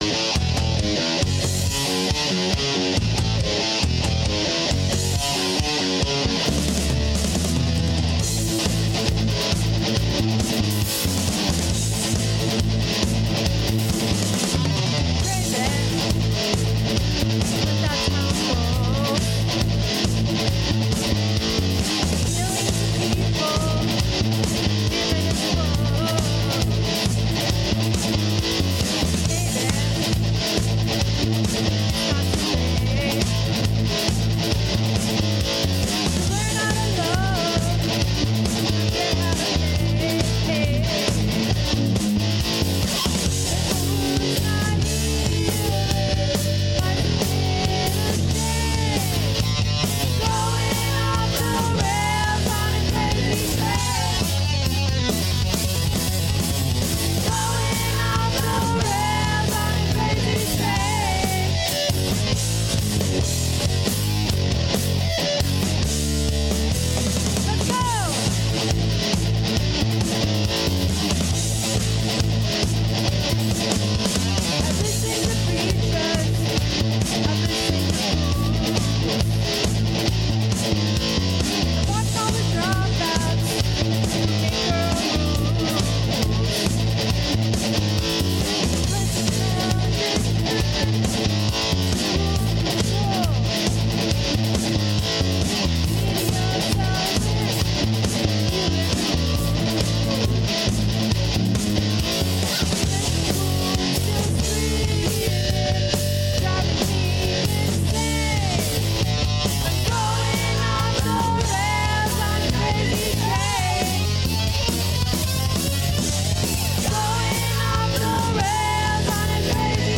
Mile High Karaoke Live Wednesday through Saturday 9-1 Broadways Shot Spot